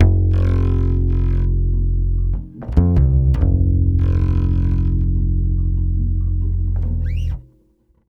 140BAS FM7 4.wav